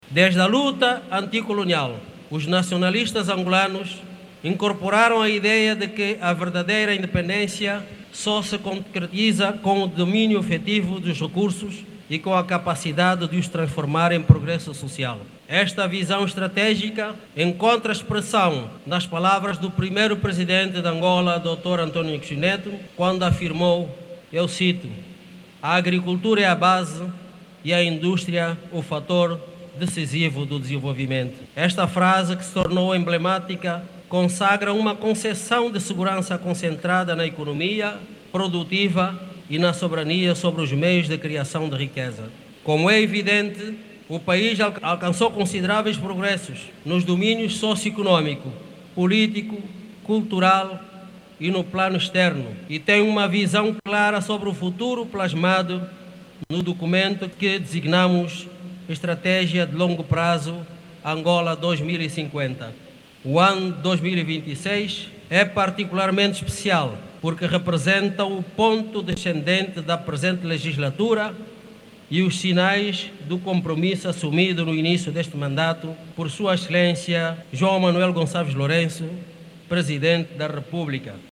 Francisco Pereira Furtado que falava em Cabinda, província que acolheu o acto central, sublinhou que o progresso socio-económico aparece depois da conquista da independência nacional como carro-chefe da governação em Angola.